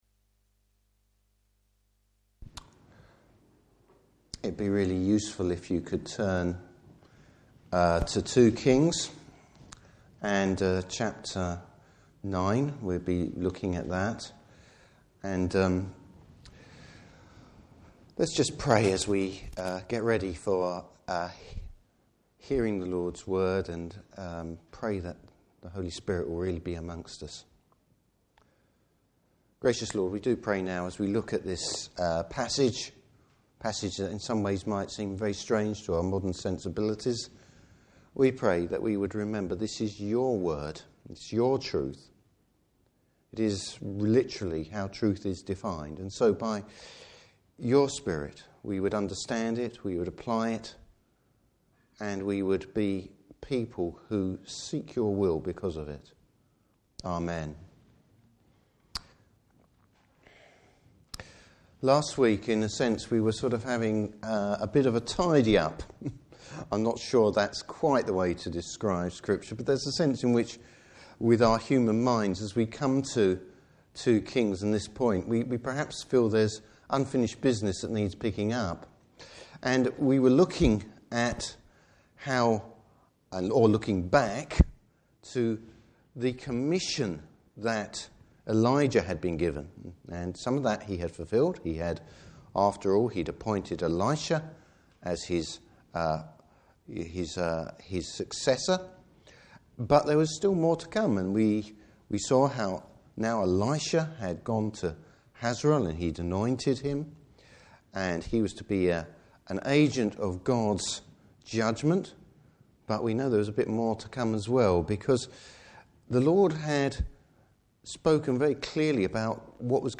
Service Type: Evening Service Bible Text: 2 Kings 9:1-29.